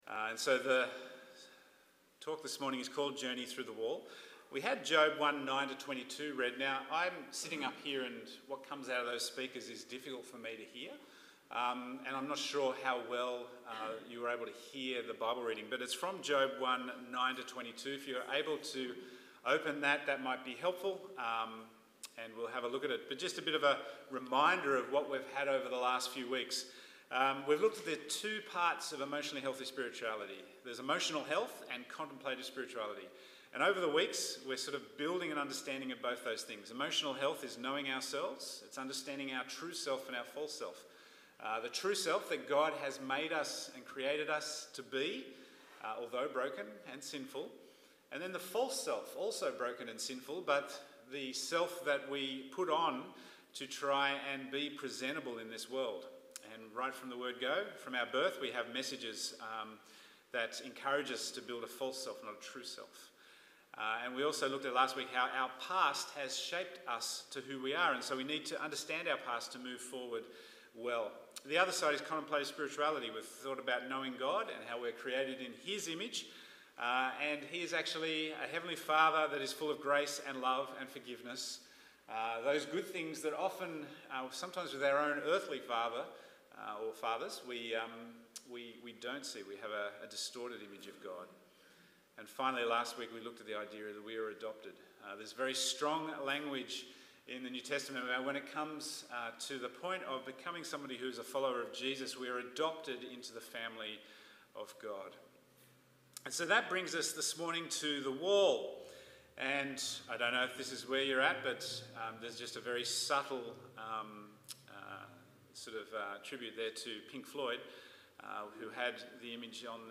Bible Text: Job 1:9-22 | Preacher